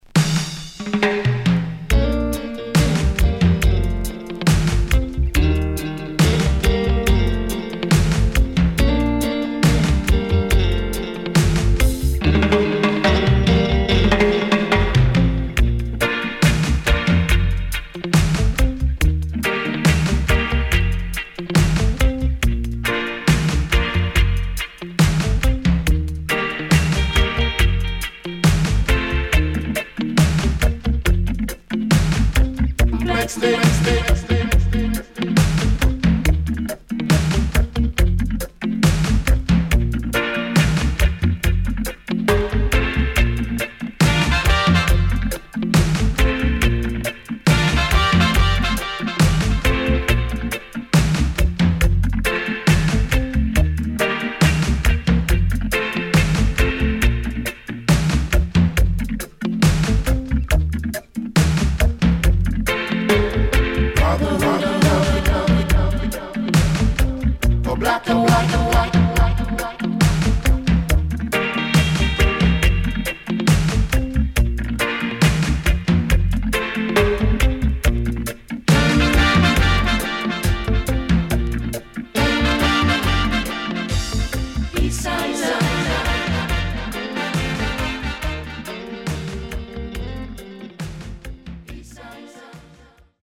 Nice Lovers Vocal.Good Condition